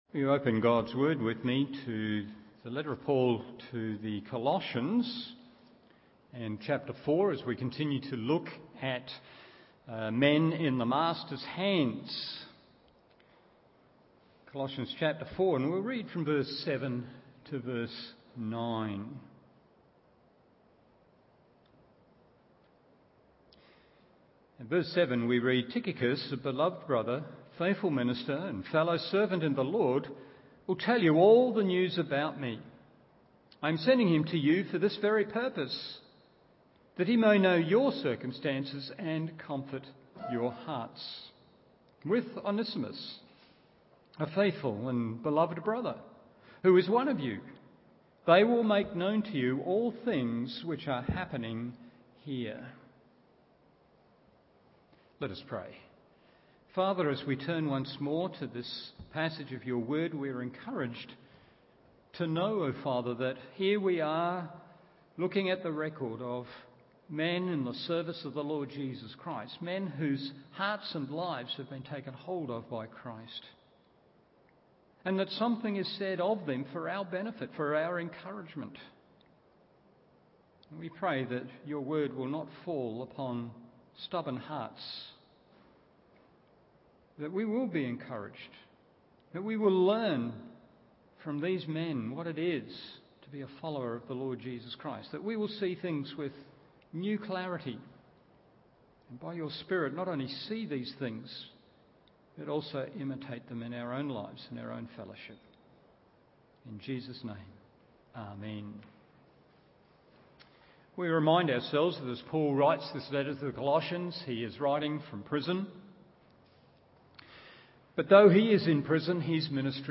Evening Service Col 4:9 1.